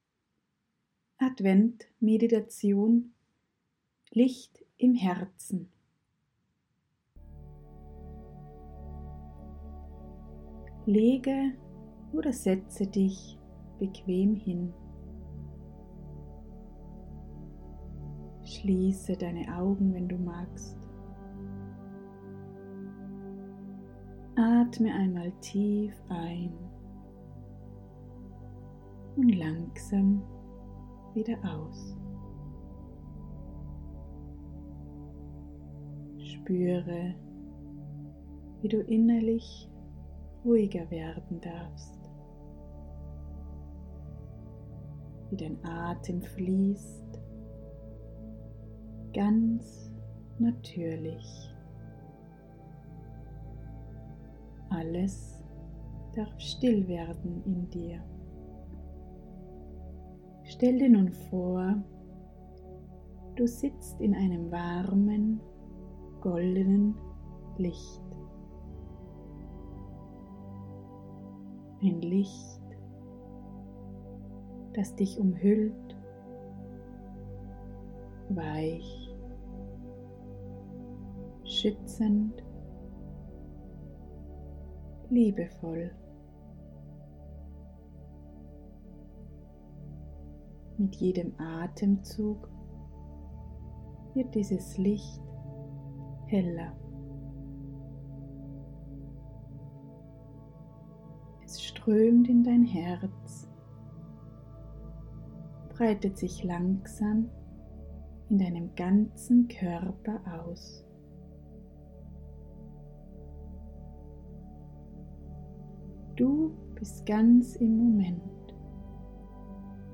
adventmeditation.mp3